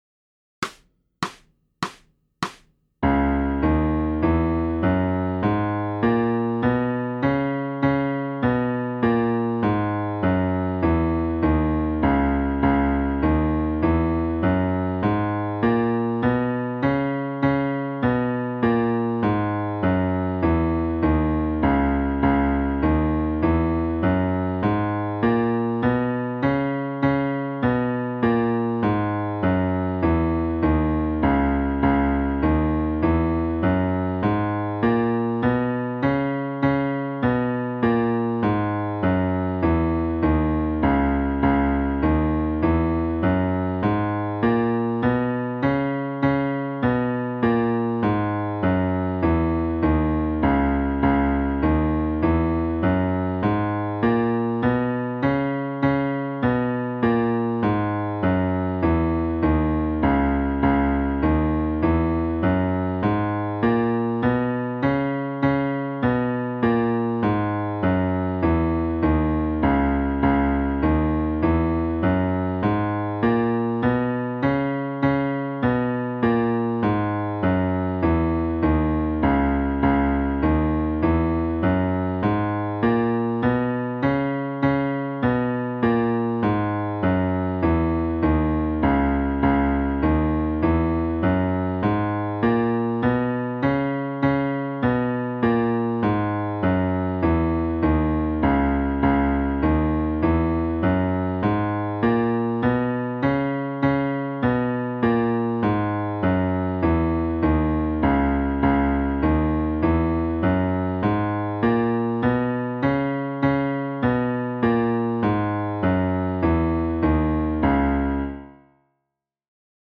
Fully notated scales, backing tracks and written guidance.
This difference gives the Dorian mode a lighter sound than the Aeolian mode.